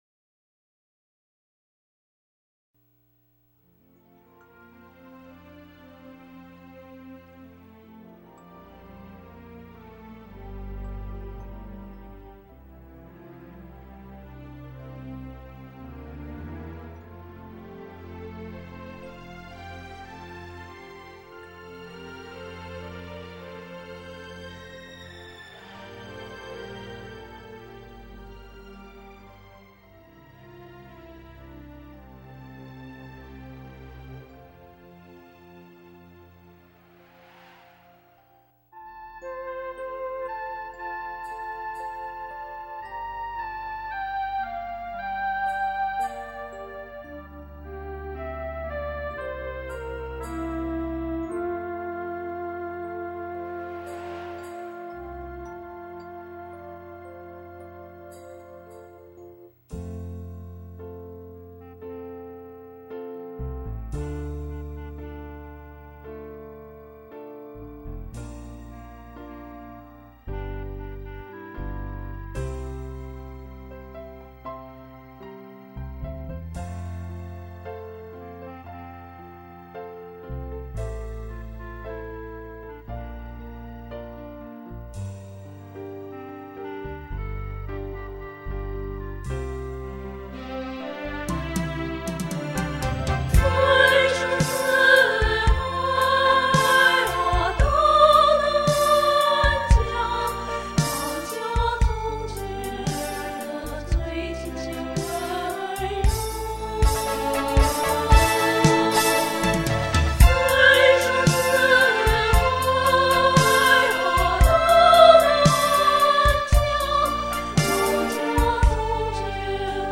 高山流水大合唱《游子情思》主旋律版
因为时间仓促，录音制作都比较粗燥，声线也比较单一，这些会在各声部录音中得到加强。
这一版高音部分比较单薄，请各位女高音在实唱录音时，音色更加饱满点，尤其是最高音部分，请给予最充实的气息支持。
这是一首感情真切，曲调优美动人的混声合唱曲，曾获1998年全国首届合唱比赛新作品一等奖。